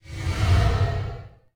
Scrape.wav